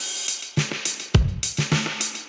Index of /musicradar/dub-designer-samples/105bpm/Beats
DD_BeatA_105-02.wav